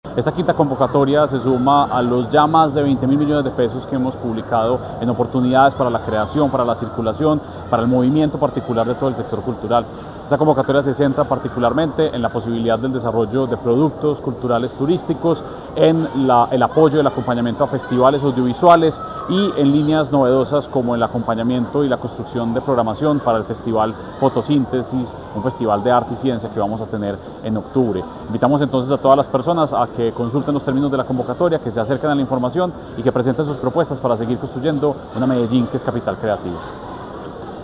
Declaraciones del secretario de Cultura Ciudadana, Santiago Silva Jaramillo.
Declaraciones-del-secretario-de-Cultura-Ciudadana-Santiago-Silva-Jaramillo..mp3